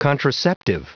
Prononciation du mot contraceptive en anglais (fichier audio)